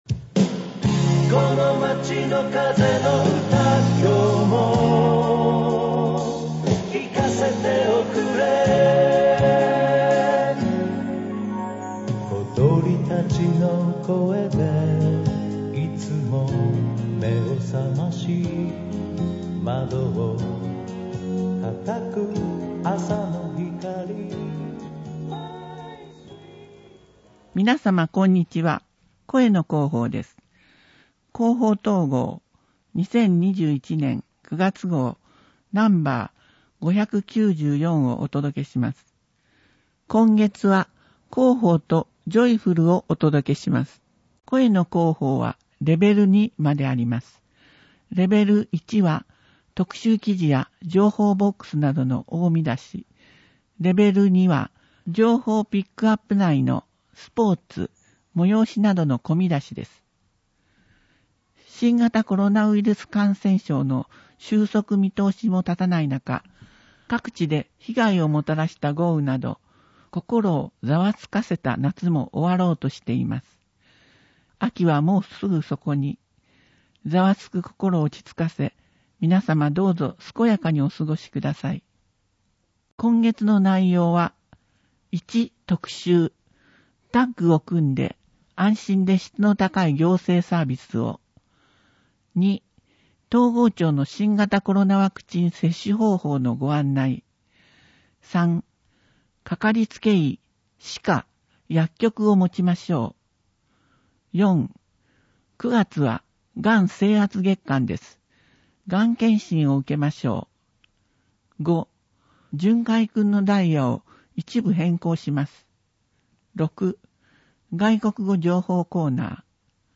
広報とうごう音訳版（2021年9月号）